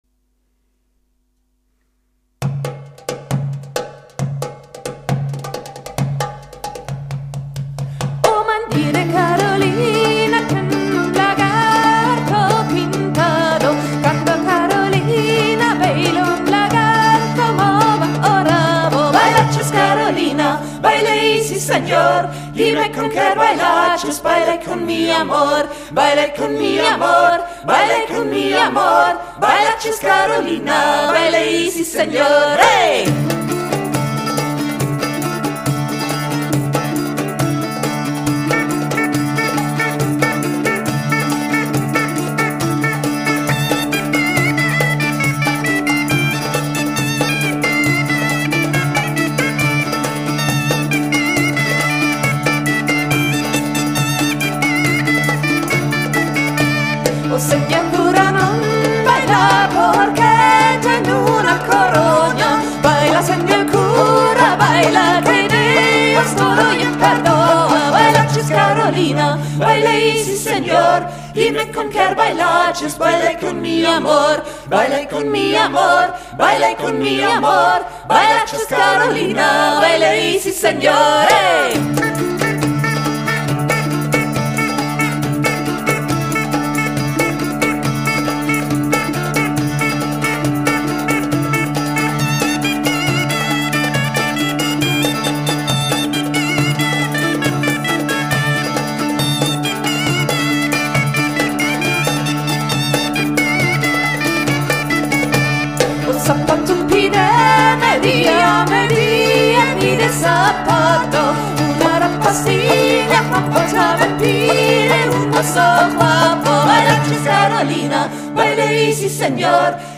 Mittelalter
Dudelsack